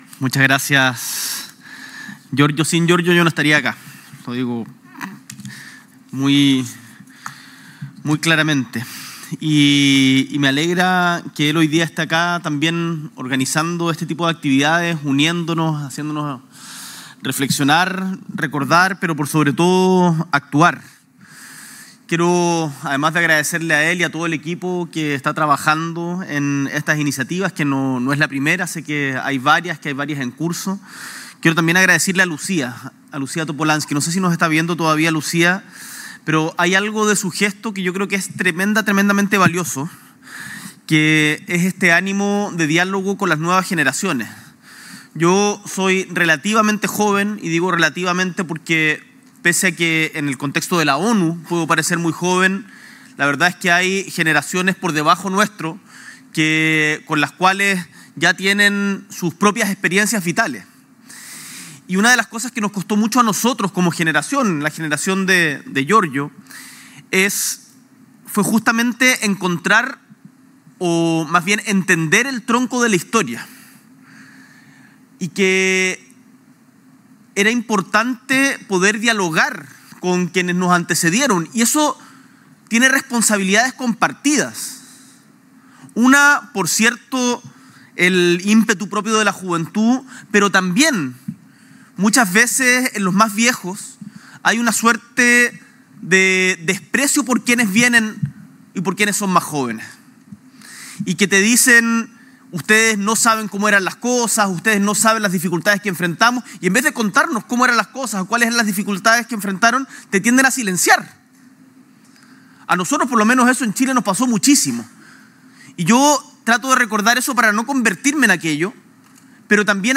S.E. el Presidente de la República, Gabriel Boric Font, participa de evento especial en honor al presidente de la República Oriental del Uruguay, José "Pepe" Mujica
Discurso